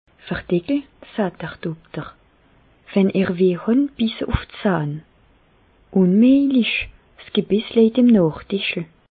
Bas Rhin
Ville Prononciation 67
Reichshoffen